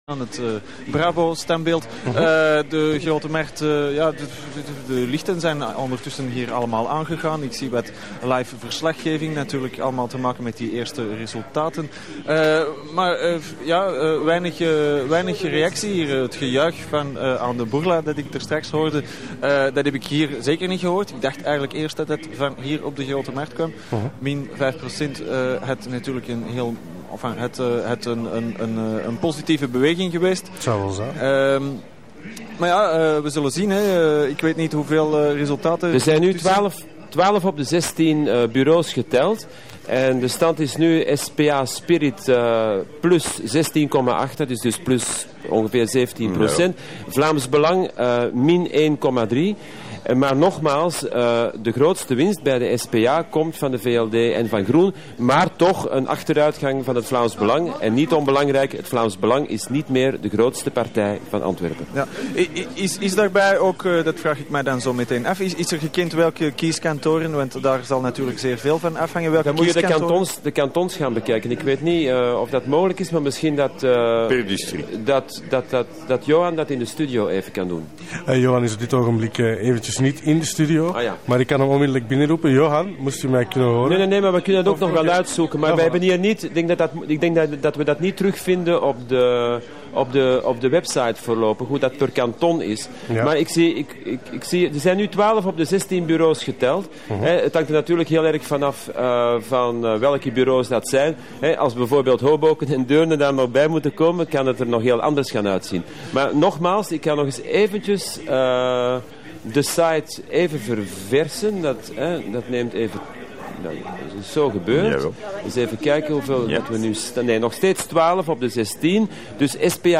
Verkiezingshow op Radio Centraal: Uitslagen in Antwerpen